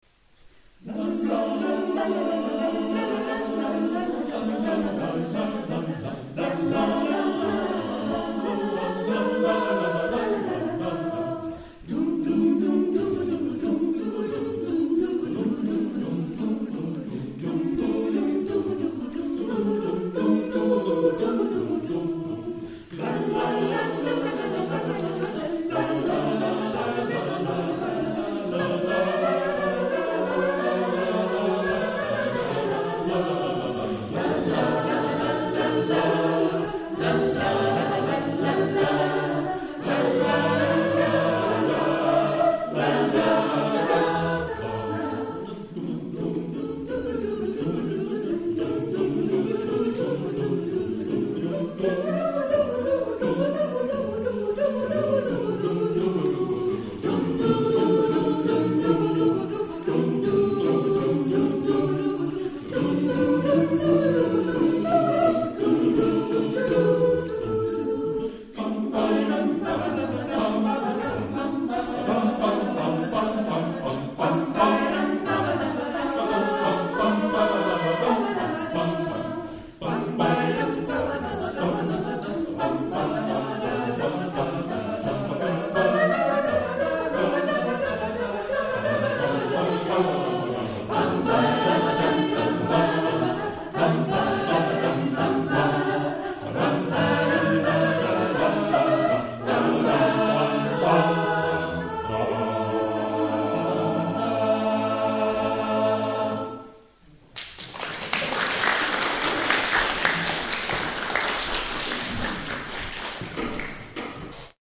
DaCap o - K�rkv�ll i Ragvaldstr�sk - 2009-03-11 N�gra s�nger fr�n n�r vi sj�ng i Ragvaldstr�sk Aftontankar vid Fridas ruta.mp3 2,4 MB Av l�ngtan till dig.mp3 3,1 MB Bouree.mp3 1,8 MB Frasse.mp3 1,3 MB Mitt eget land.mp3 3,8 MB